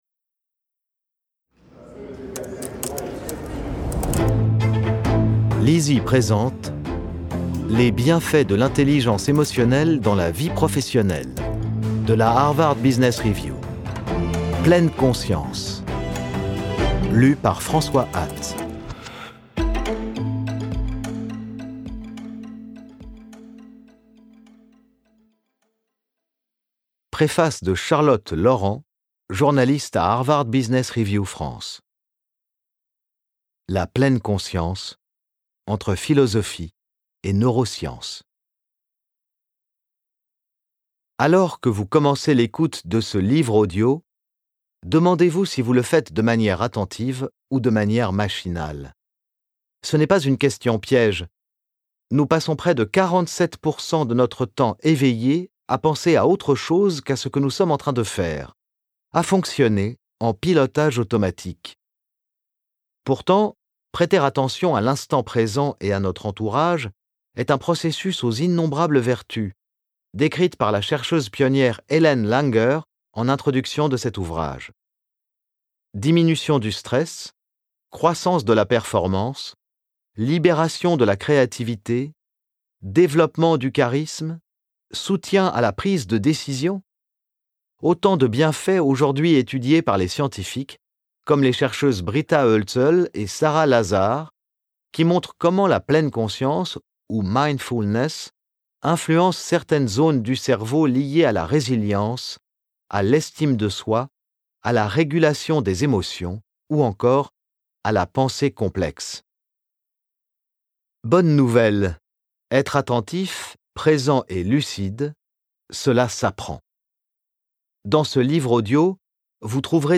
Ce livre audio fait partie de la collection Les Bienfaits de l'intelligence émotionnelle dans la vie professionnelle .